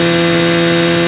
Engines